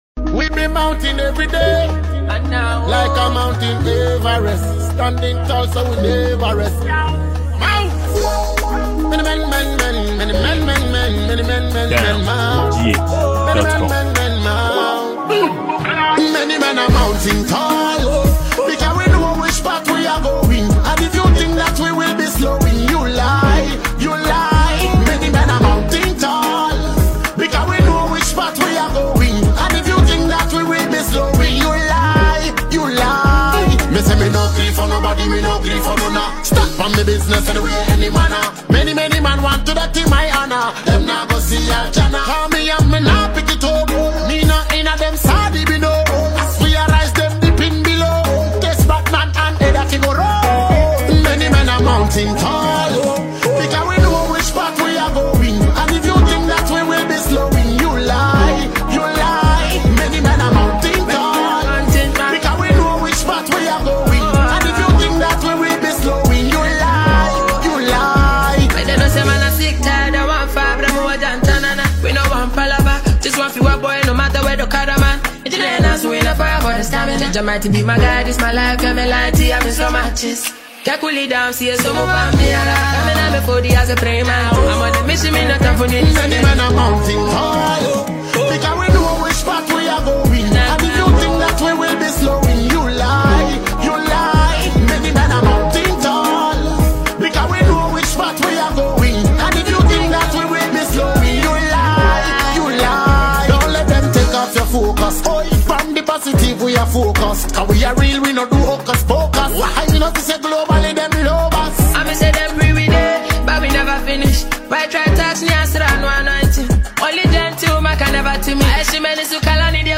a song unleashed by Ghanaian afrobeats singer and songwriter